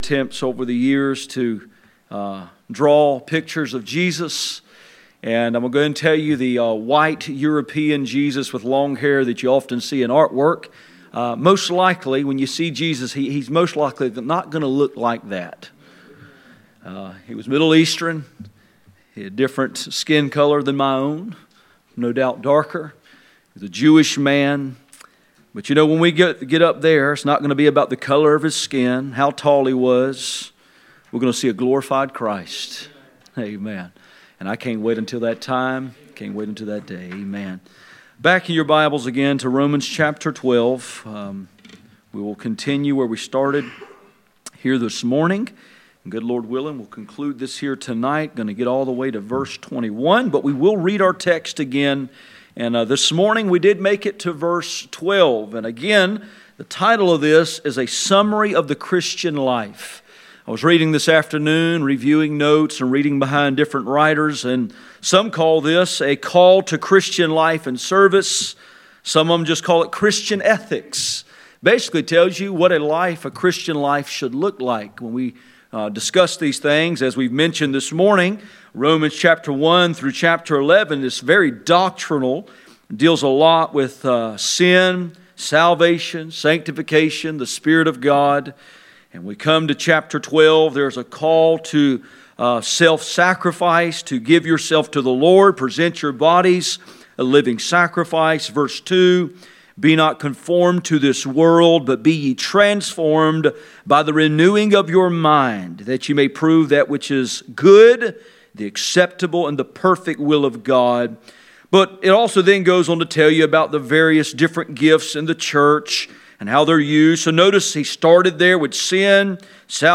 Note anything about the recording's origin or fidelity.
Passage: Romans 12:9-21 Service Type: Sunday Evening